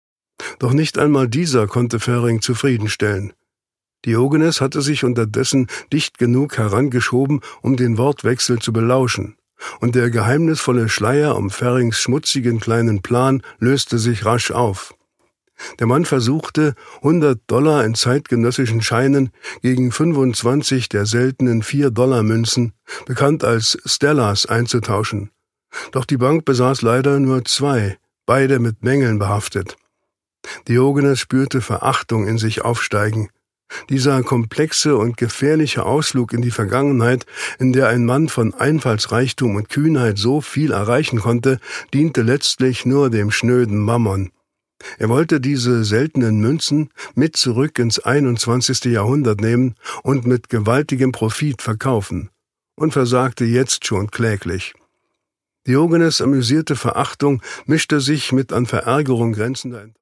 Produkttyp: Hörbuch-Download
Gelesen von: Detlef Bierstedt